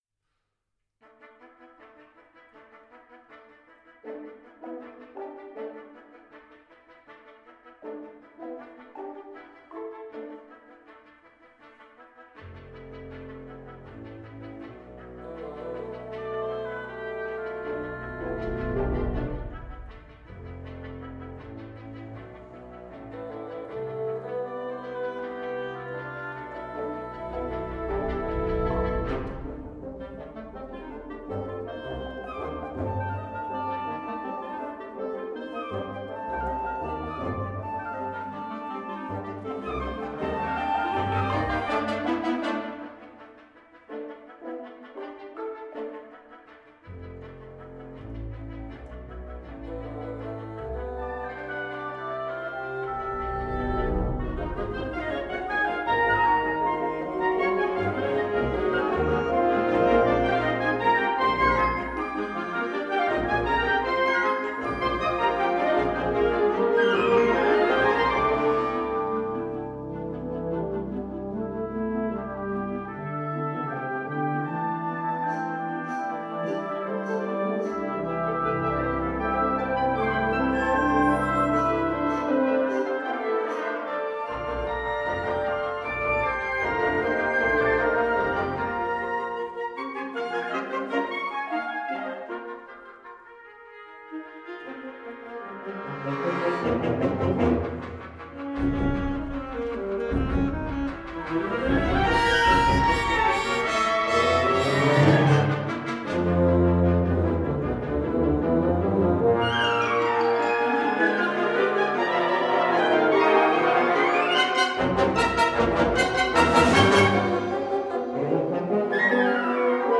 Categoria Concert/wind/brass band
Instrumentation Ha (orchestra di strumenti a faito)